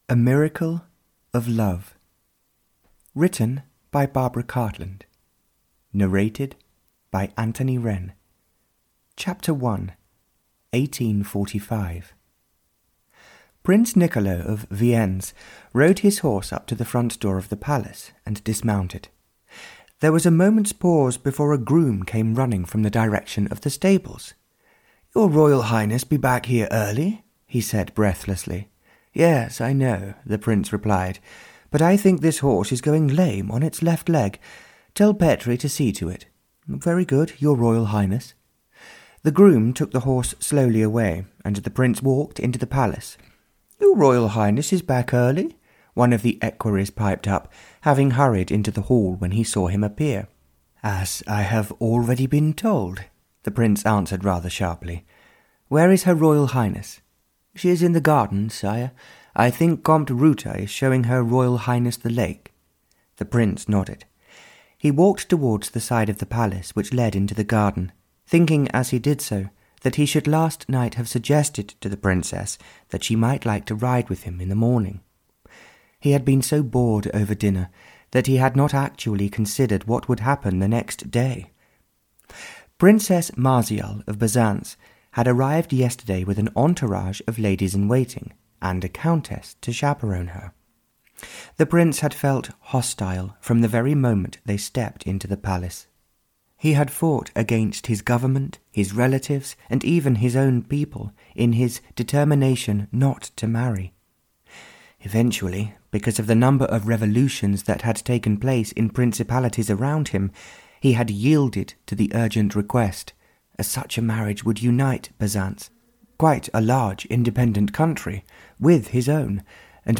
A Miracle of Love (Barbara Cartland s Pink Collection 88) (EN) audiokniha
Ukázka z knihy